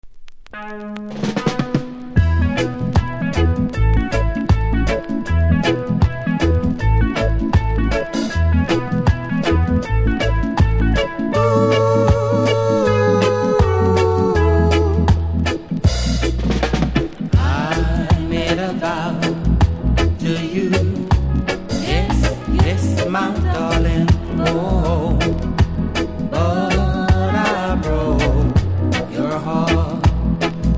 REGGAE 70'S
多少うすキズありますが音は良好なので試聴で確認下さい。